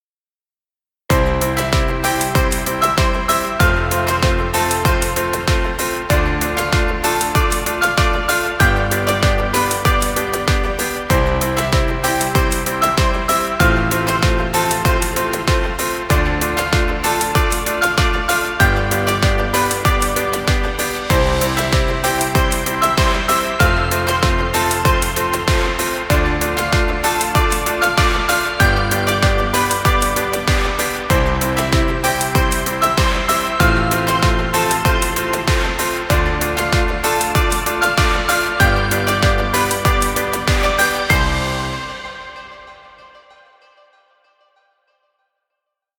Children music.
Stock Music.